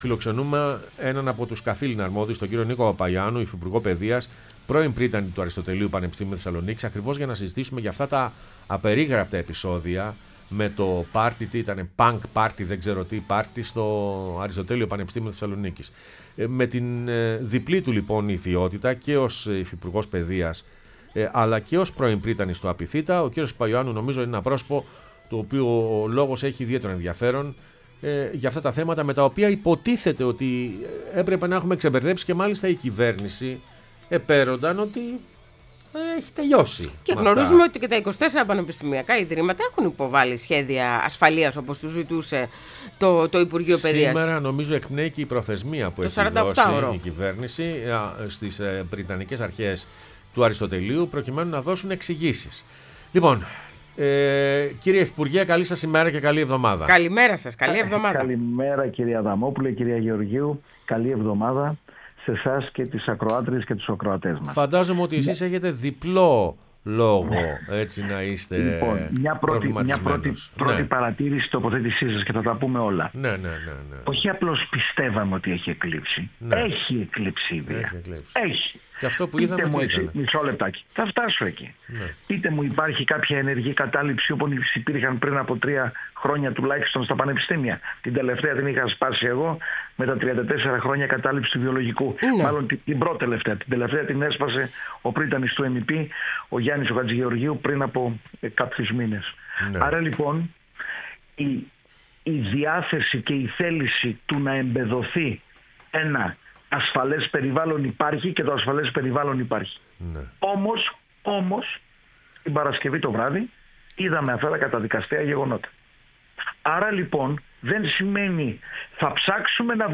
Νίκος Παπαιωάννου, Υφυπουργός Παιδείας και πρώην πρύτανης ΑΠΘ, μίλησε στην εκπομπή «Πρωινές Διαδρομές»